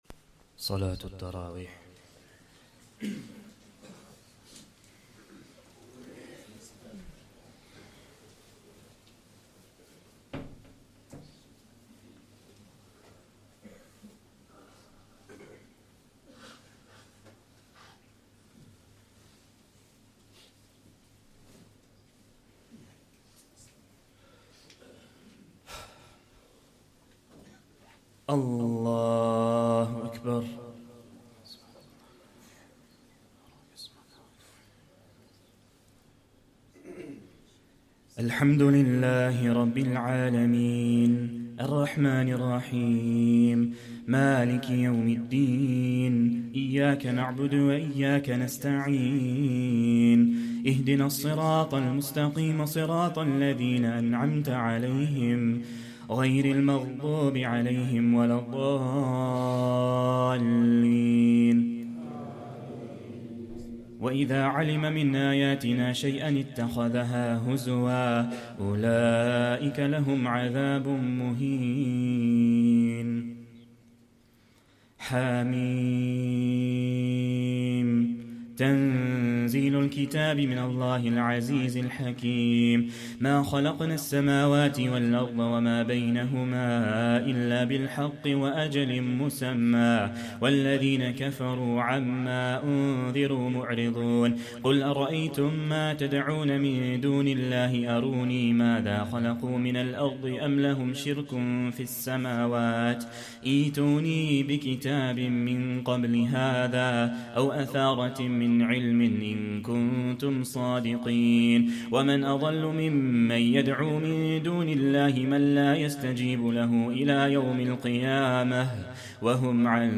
2nd Tarawih prayer - 24th Ramadan 2024